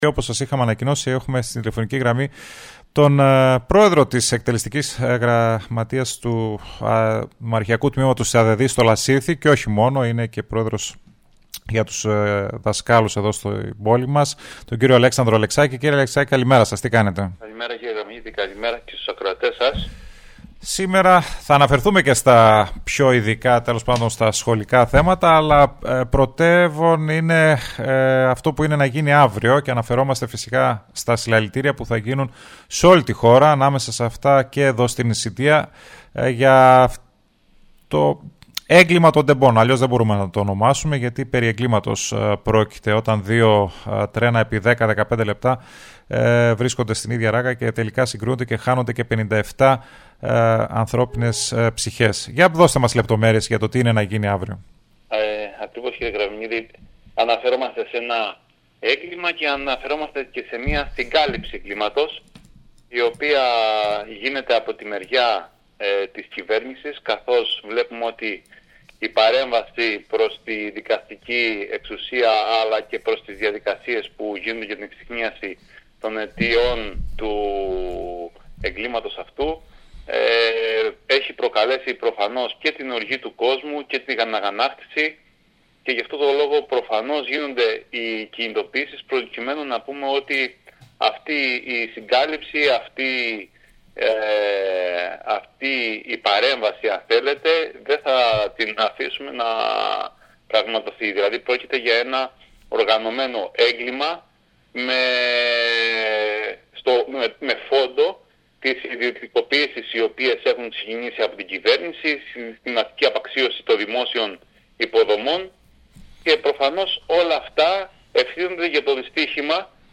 μίλησε στην πρωινή εκπομπή του Style 100